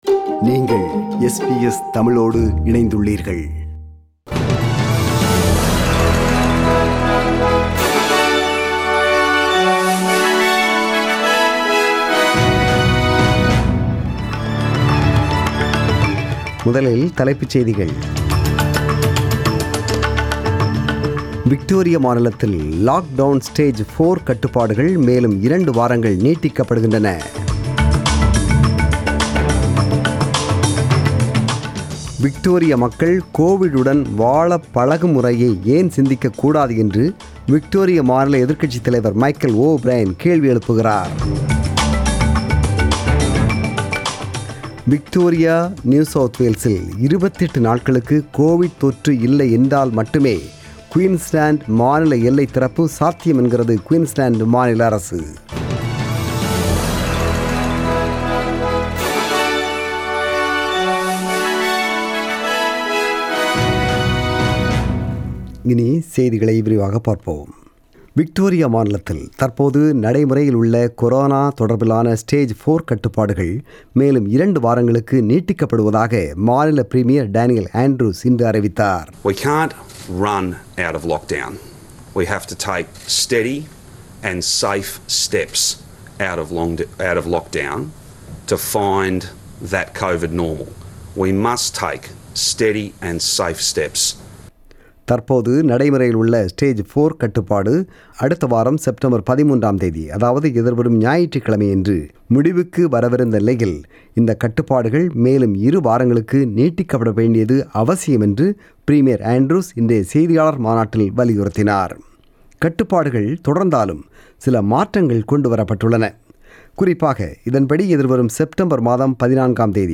The news bulletin was broadcasted on 6 September 2020 (Sunday) at 8pm.